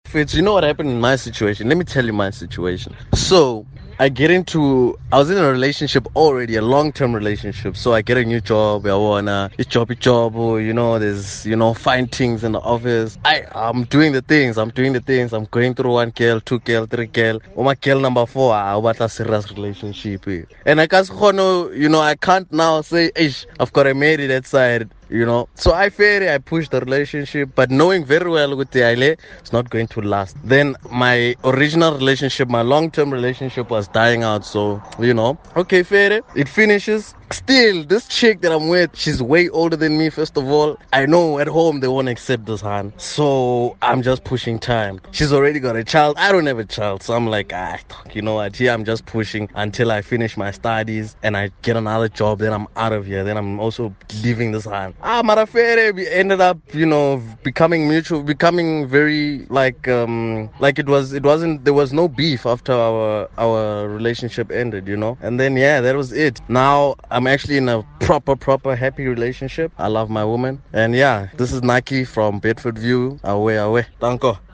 Kaya Drive listeners weigh in on dead-end relationships: